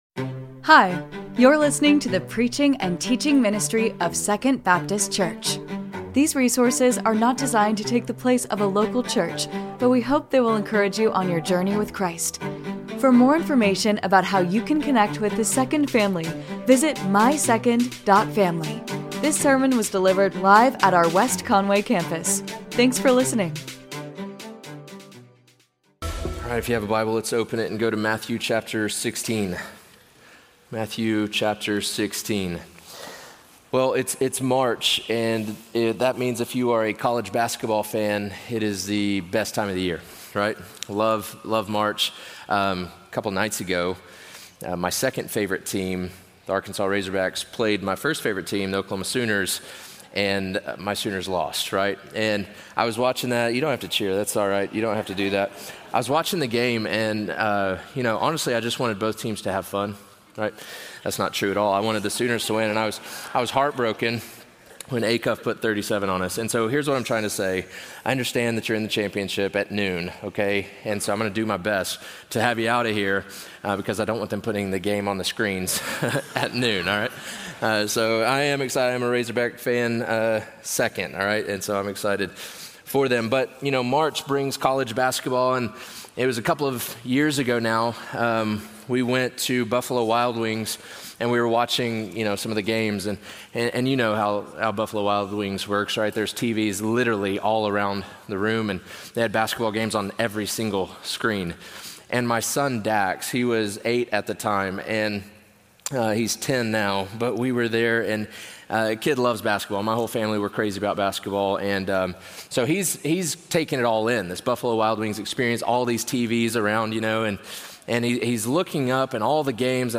This is the podcast of the teaching and preaching ministry of 2ND Baptist Church